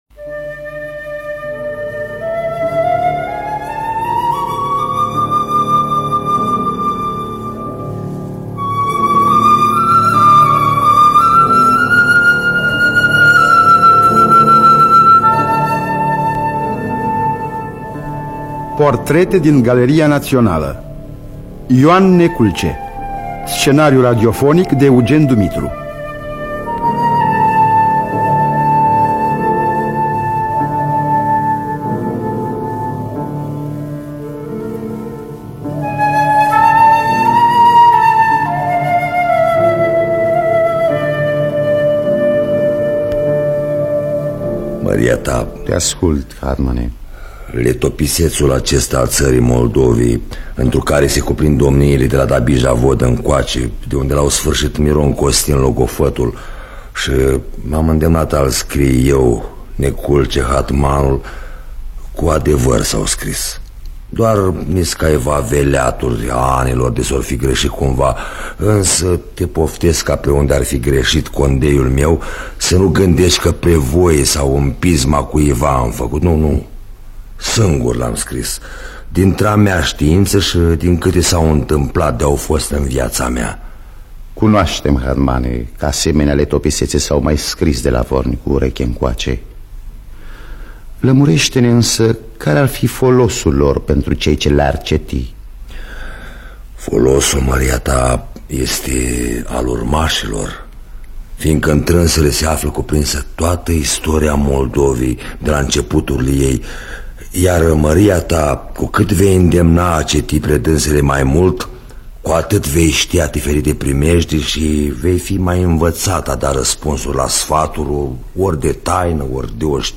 Scenariu radiofonic de Eugen Dumitru.